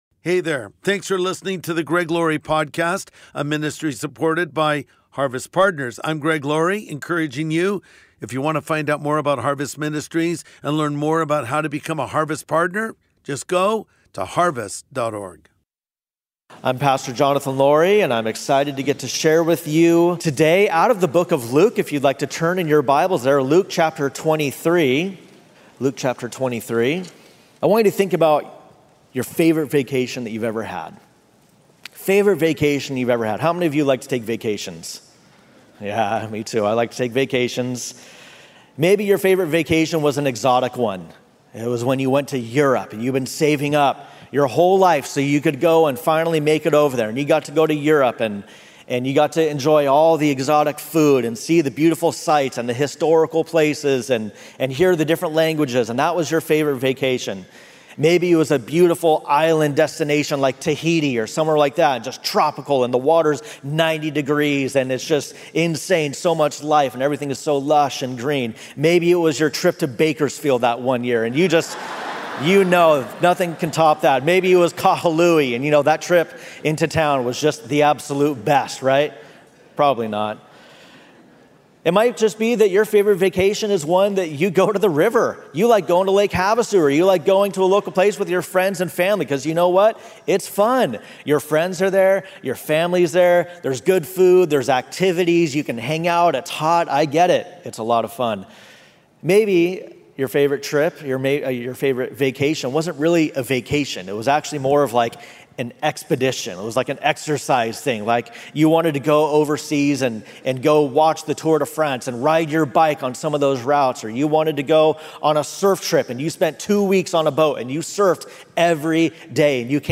See You in Paradise | Sunday Message